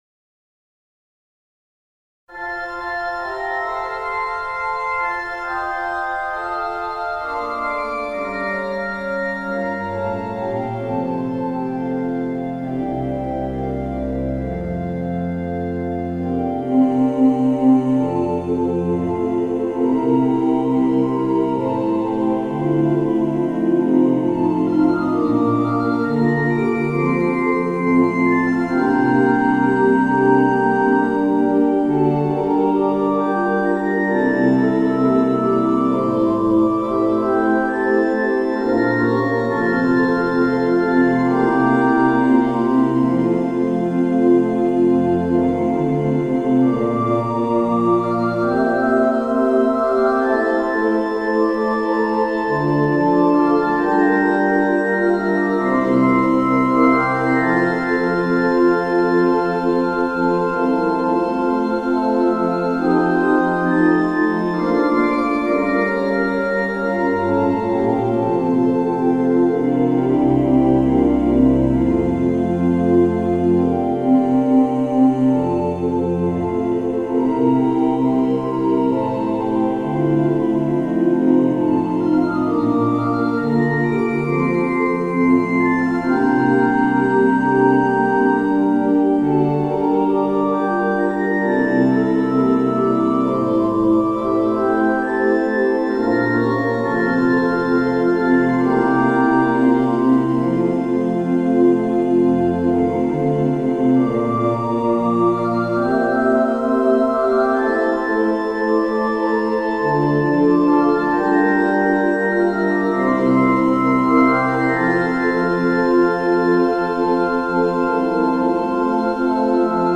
Primeste jertfa mea Isuse– Partitura cor, pian